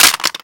Gun_Drop_and_Metalli_1.wav